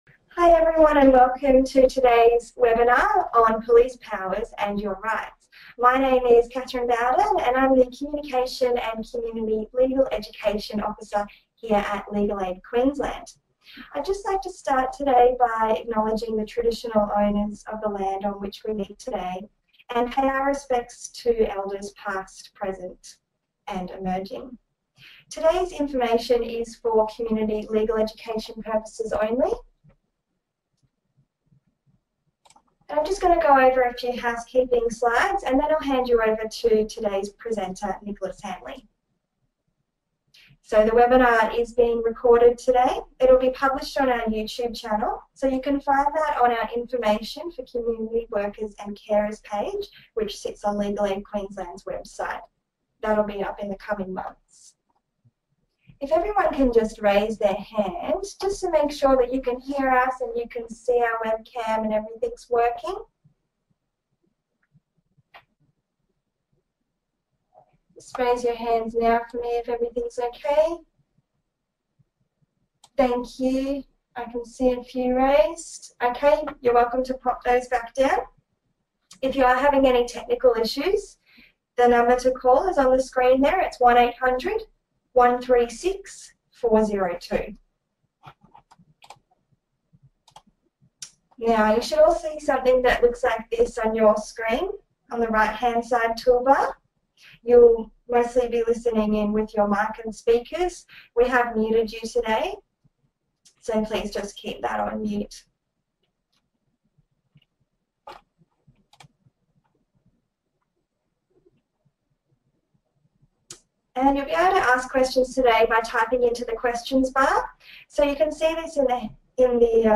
Legal Aid Queensland held a free webinar for community workers on Police powers and your rights: what community workers need to know.